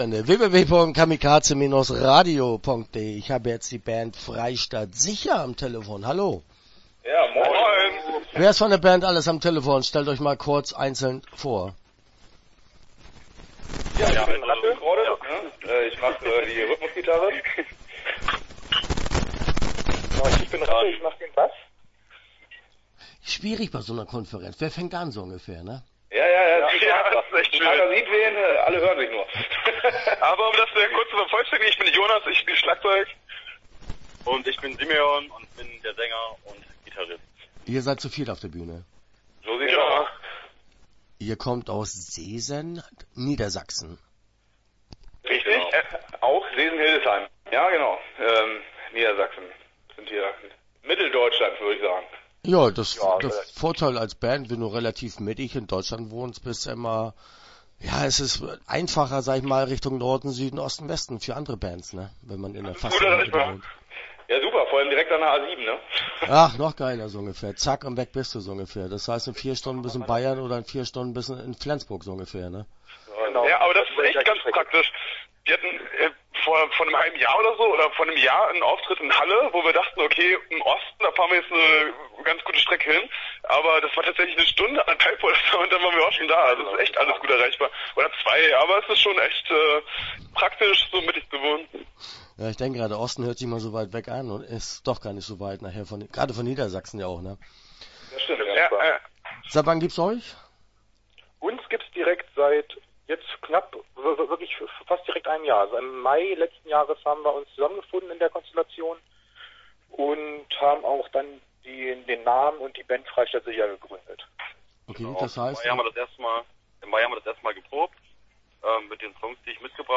Frei Statt Sicher - Interview Teil 1 (12:34)